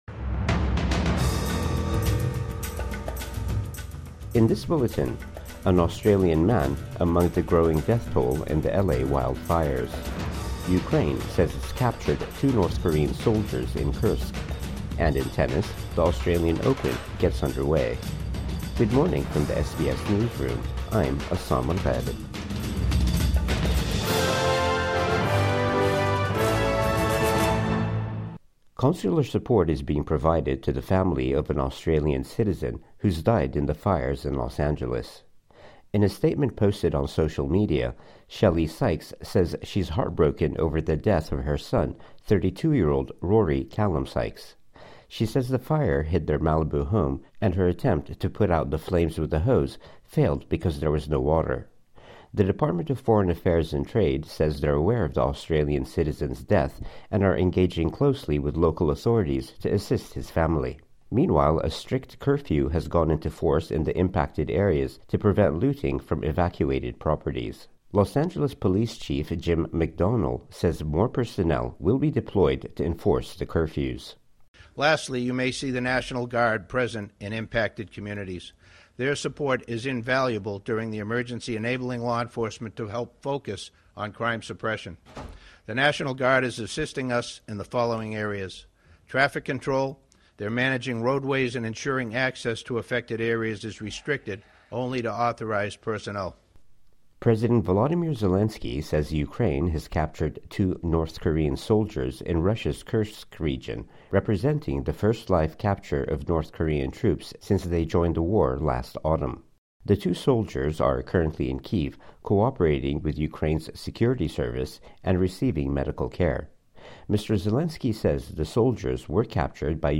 Morning News Bulletin 12 January 2025